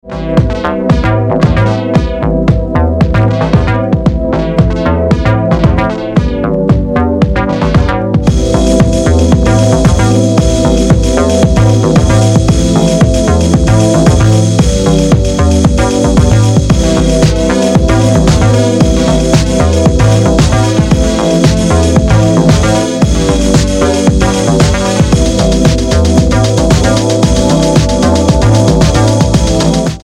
Seminal house record